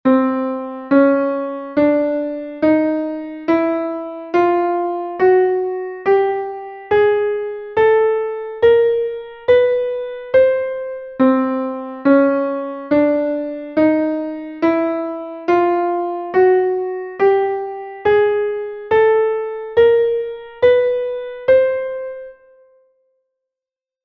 Diatonisch-chromatische Tonleiter
c cis d dis e f fis g gis a ais h c
Diatonisch-chromatische-Tonleiter.mp3